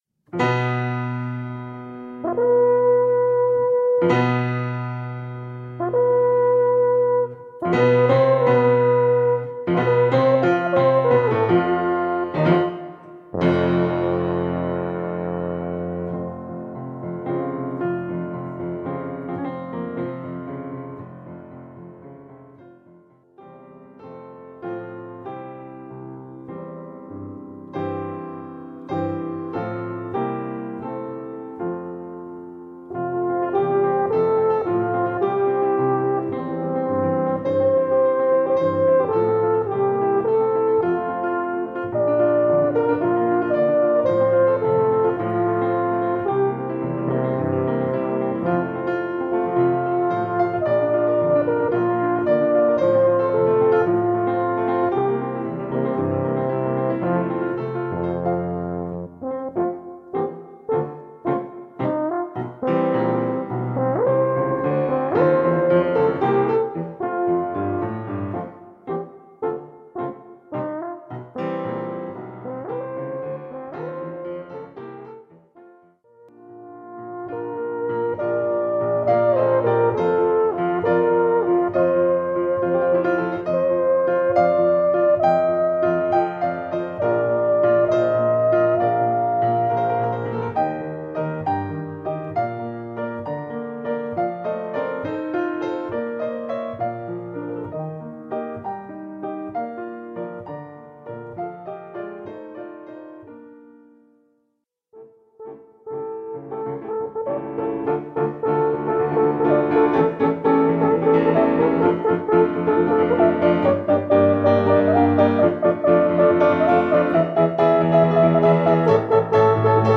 Kompositionen Alphorn (Solo bis Trio) mit Klavier